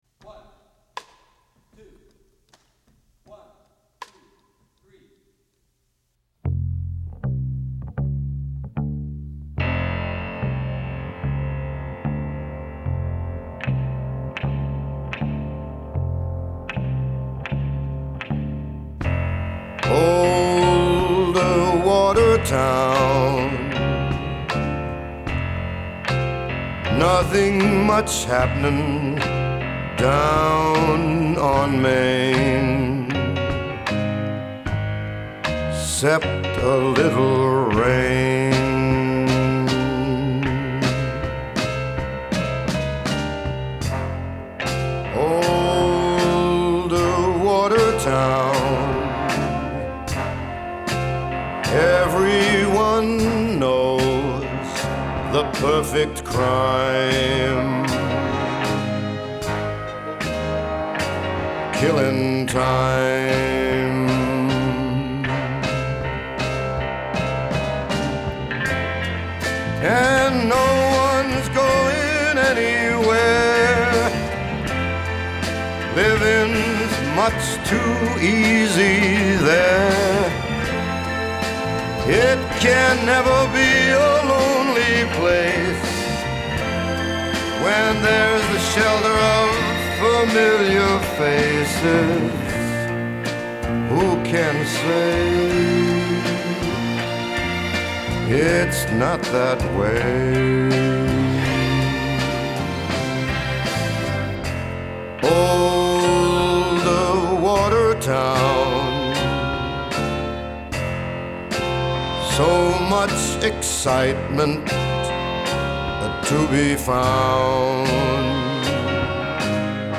Genre : Lounge, Électronique